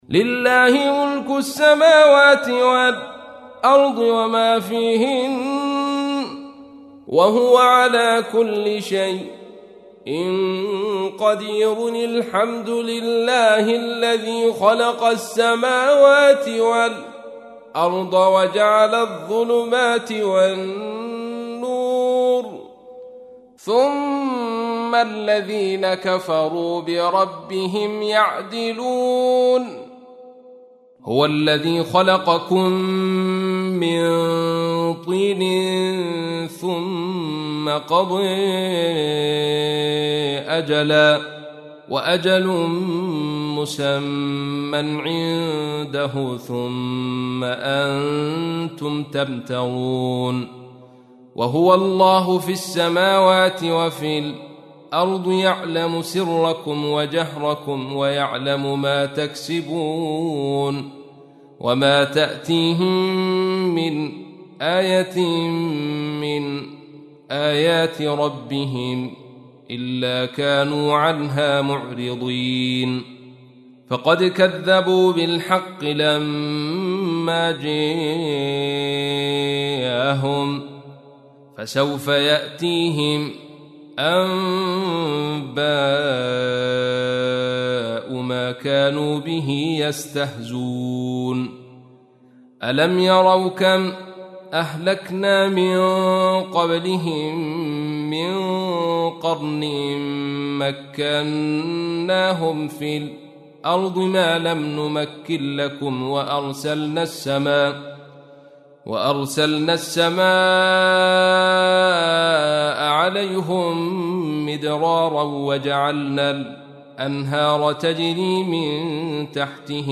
تحميل : 6. سورة الأنعام / القارئ عبد الرشيد صوفي / القرآن الكريم / موقع يا حسين